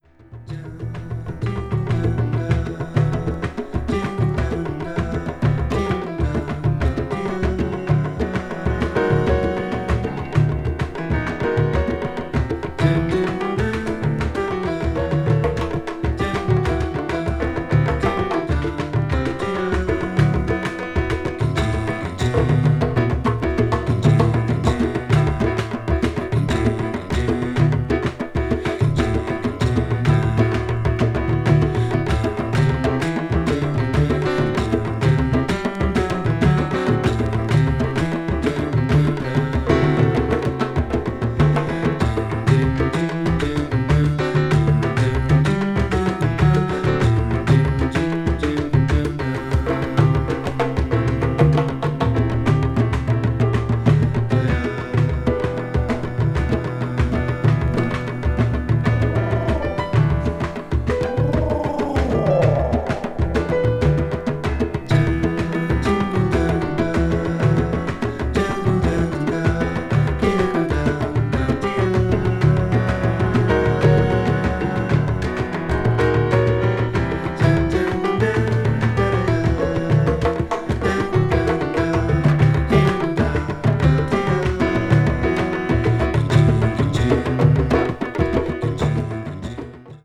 crossover   ethnic jazz   fusion   jazz groove   latin jazz